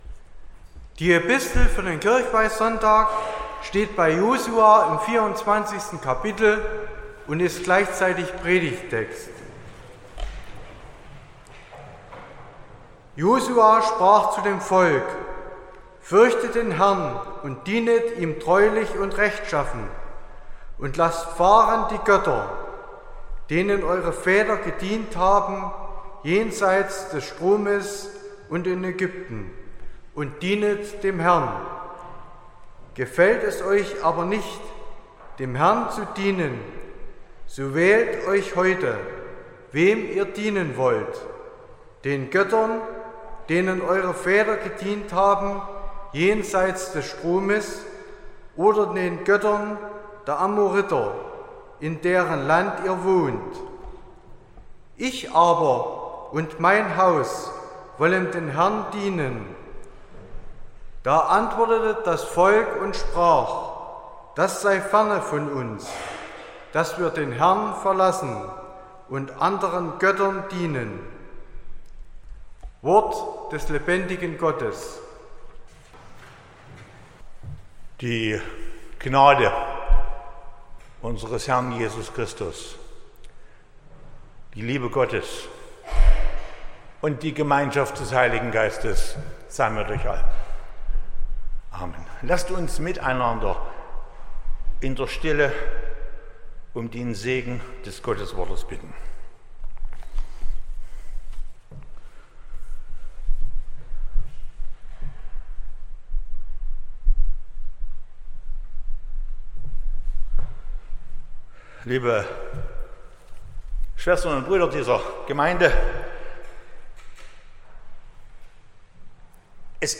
27.10.2024 – Kirchweihfestgottesdienst
Predigt und Aufzeichnungen